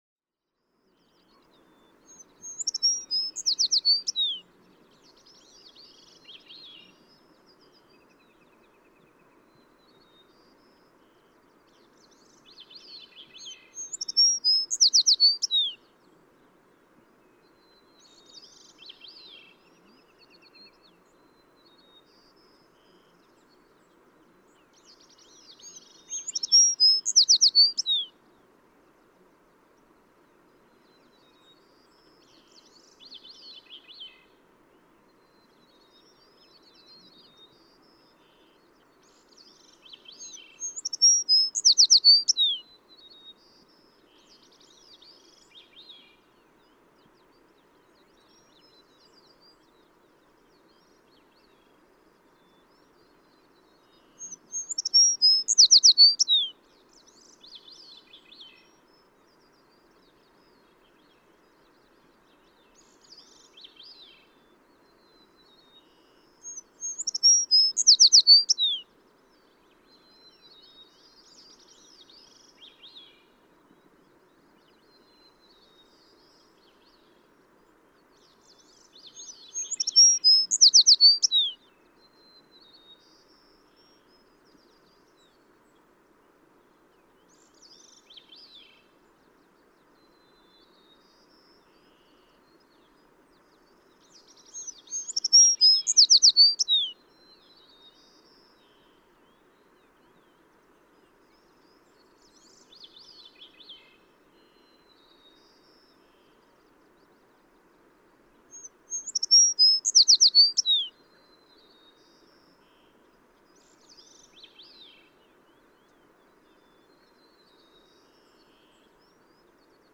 American tree sparrow
♫276, ♫277, ♫278—longer recordings from those three individuals
278_American_Tree_Sparrow.mp3